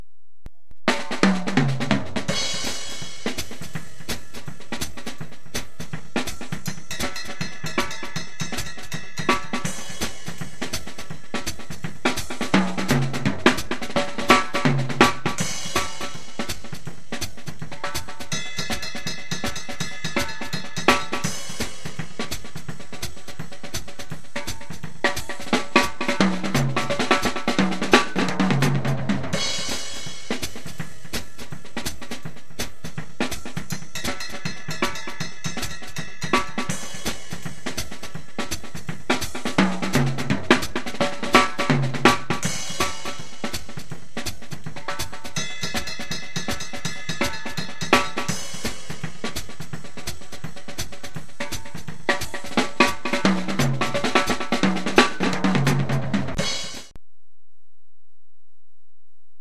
SWINGOVÉ PŘEHRÁVÁNÍ
jazzovka.mp3